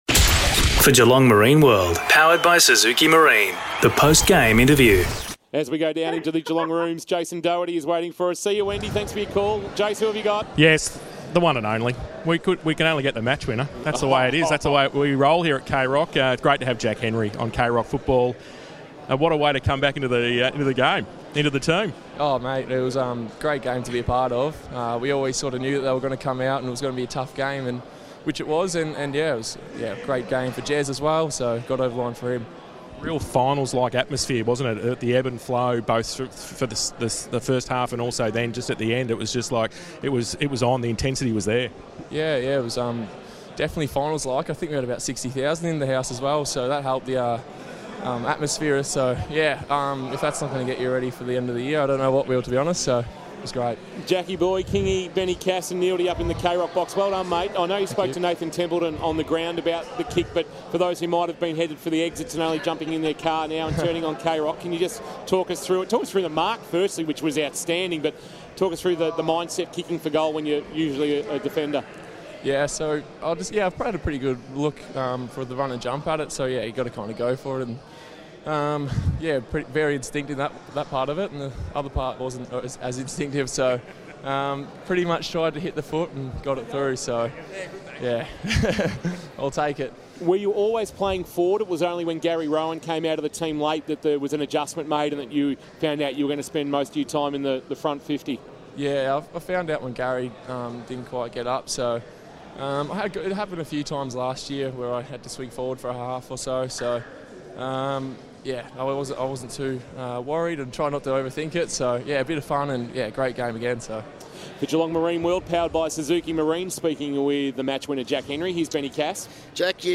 2022 – AFL ROUND 15 – GEELONG vs. RICHMOND: Post-match Interview